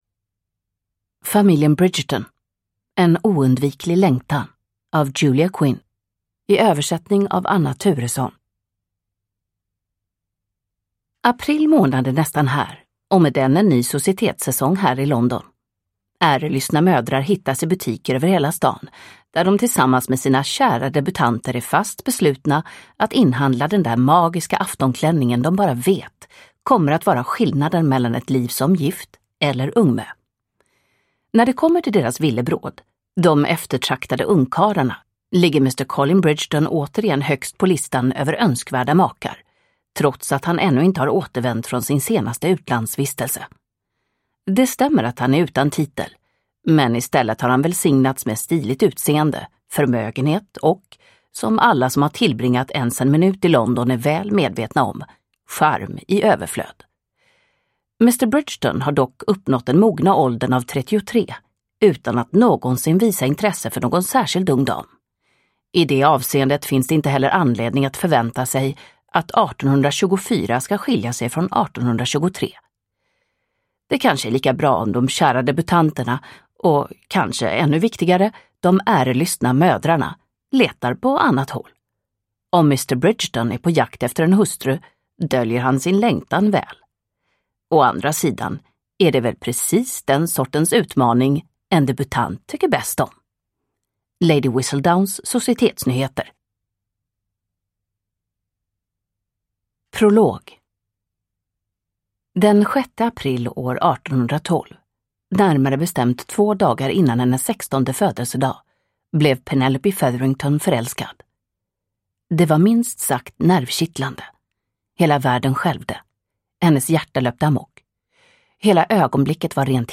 En oundviklig längtan – Ljudbok – Laddas ner